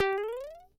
Sound effect of Super Mario Jump High in Super Mario Bros. Wonder
SMBW_Super_Mario_Jump_High.oga